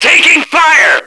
Index of /action/sound/radio/male